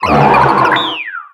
Cri de Cryptéro dans Pokémon X et Y.